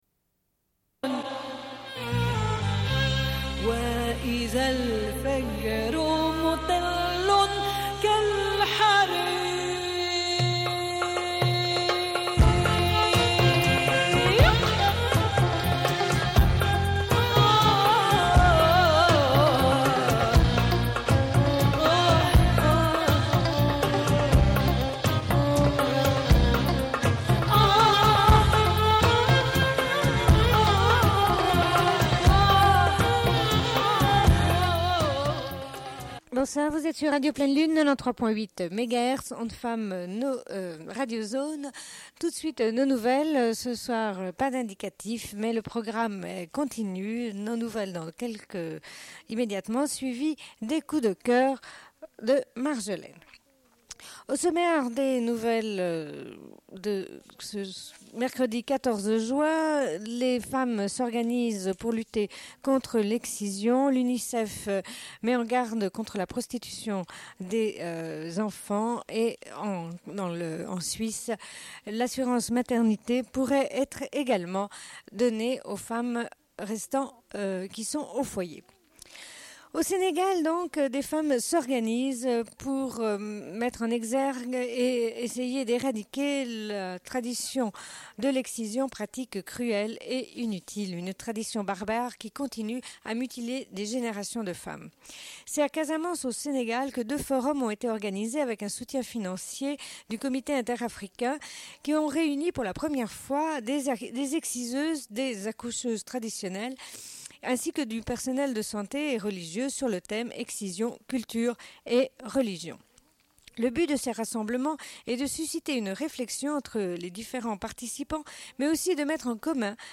Bulletin d'information de Radio Pleine Lune du 14.06.1995 - Archives contestataires
Une cassette audio, face B31:07